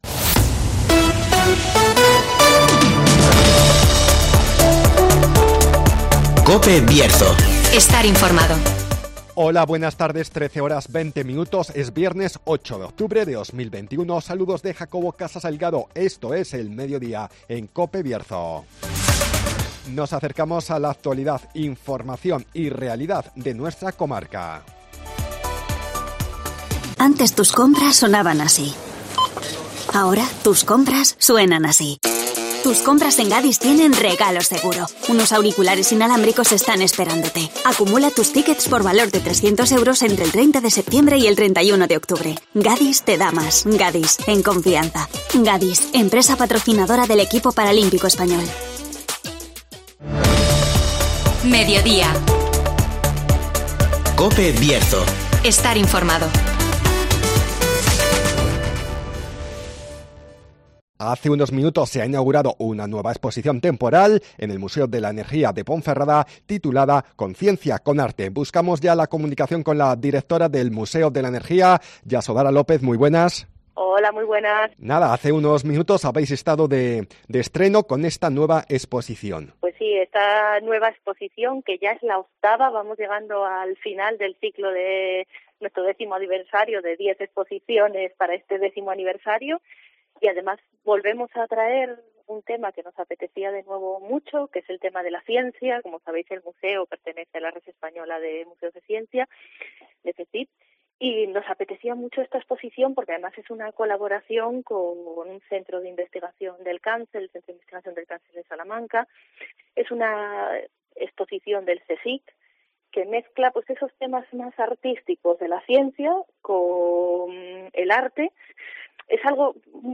Nos acercamos al Museo de la Energía de Ponferrada (Entrevista